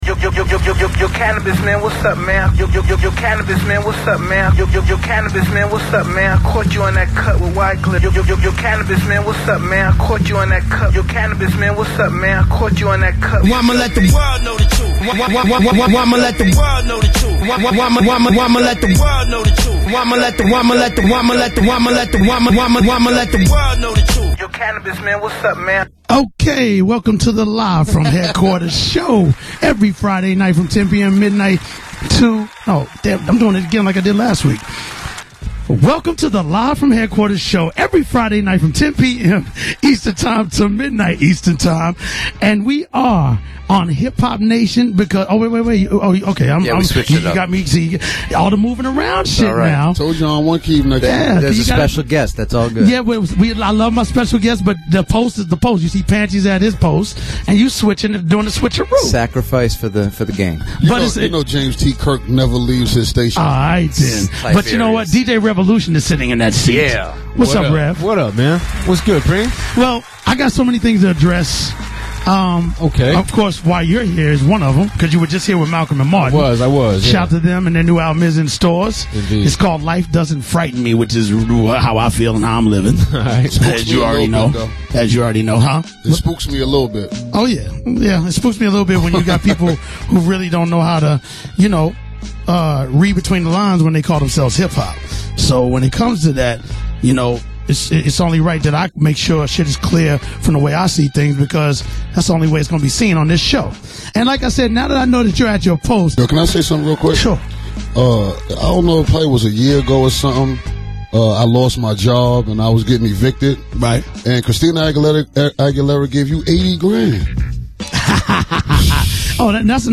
Premier took to his radio show last night to address Canibus' accusations, particularly his negative comments about D&D Studios ("dirty ass D&D") and his financial compensation from working with Christina Aguilera (stating Preem received $80,000 a track).
dj-premier-speaks-on-canibus-on-live-from.mp3